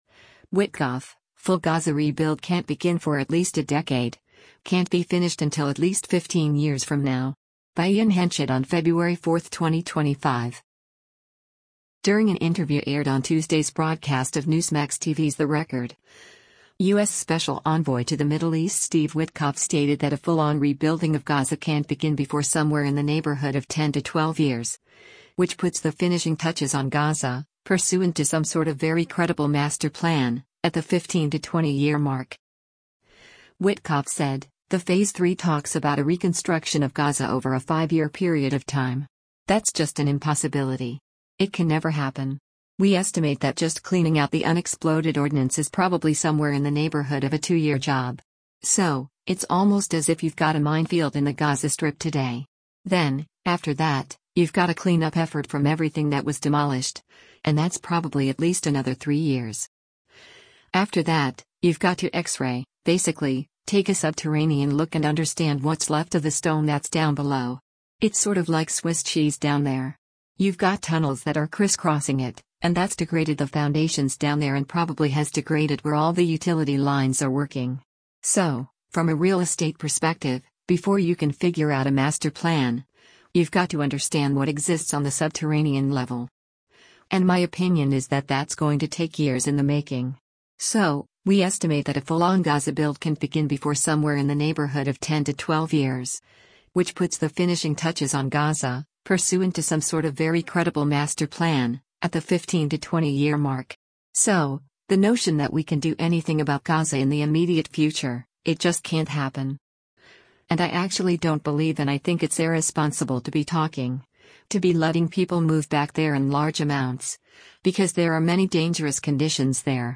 During an interview aired on Tuesday’s broadcast of Newsmax TV’s “The Record,” U.S. Special Envoy to the Middle East Steve Witkoff stated that a full-on rebuilding of Gaza “can’t begin before somewhere in the neighborhood of 10 to 12 years, which puts the finishing touches on Gaza, pursuant to some sort of very credible master plan, at the 15 to 20-year mark.”